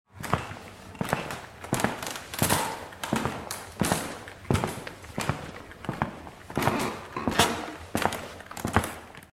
دانلود صدای راه رفتن روی چوب از ساعد نیوز با لینک مستقیم و کیفیت بالا
جلوه های صوتی